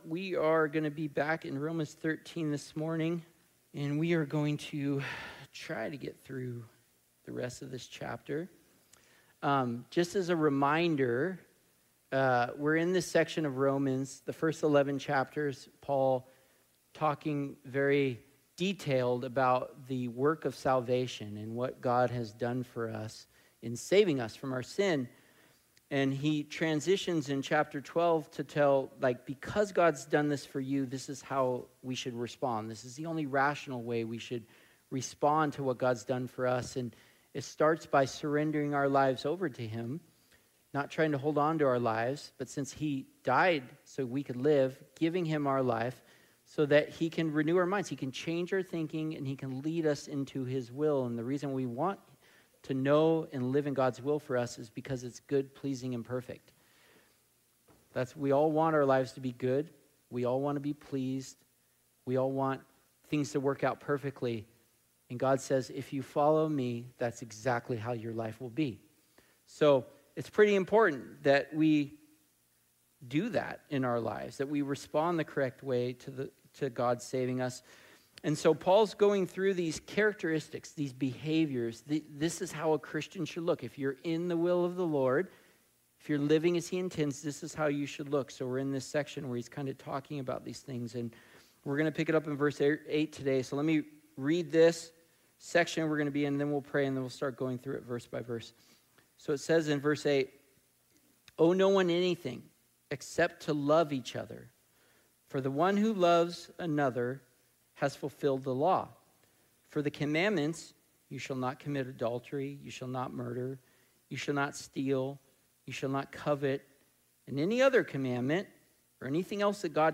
Sermons | Coastline Christian Fellowship